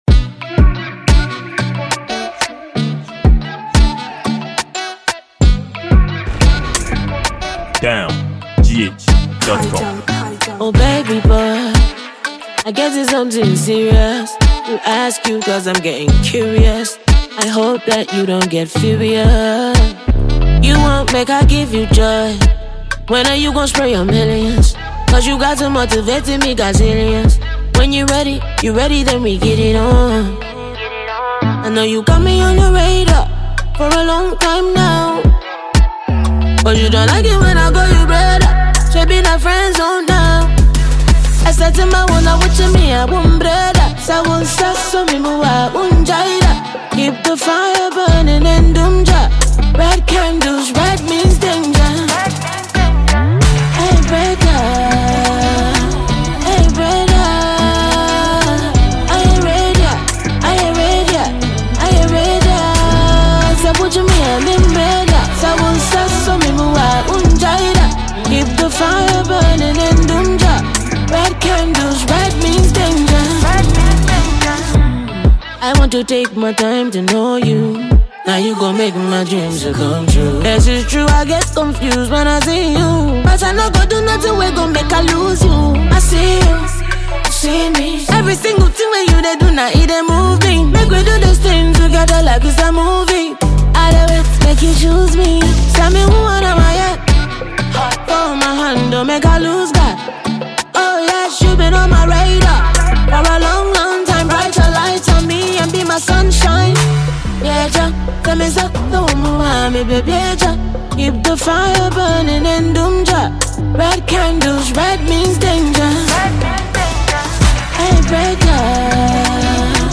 Ghanaian female songwriter, singer and musician